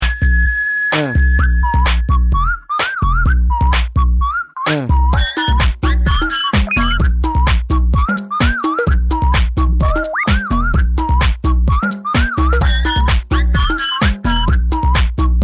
it's got a crazy whistle or something.
i got the audio from the live mix.